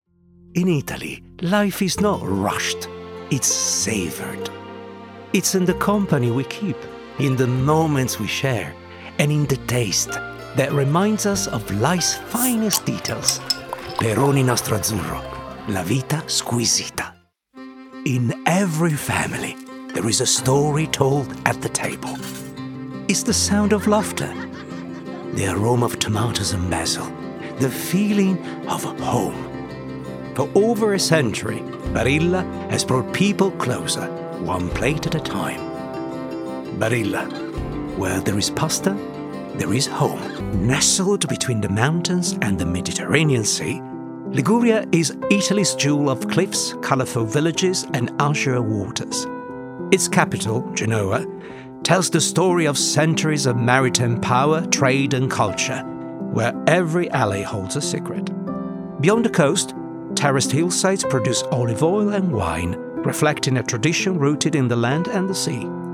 European, Italian, Male, 30s-50s